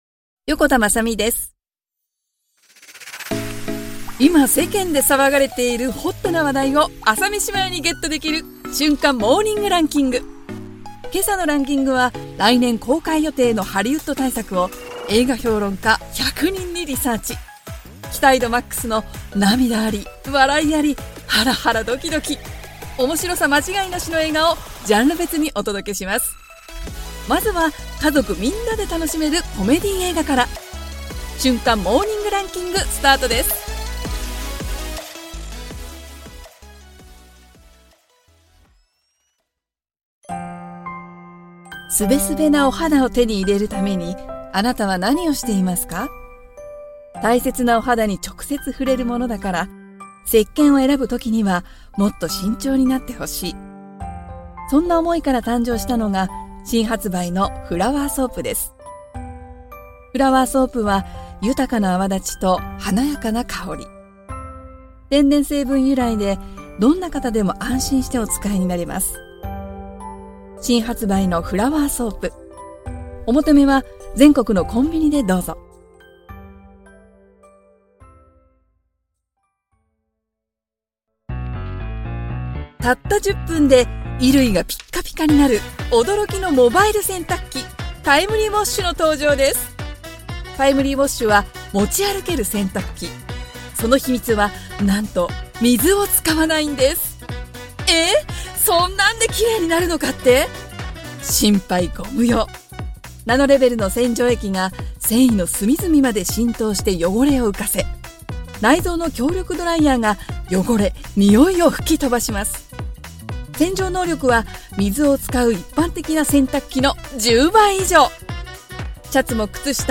ちょっとハスキーで、元気な明るい声